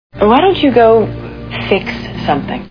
Red Planet Movie Sound Bites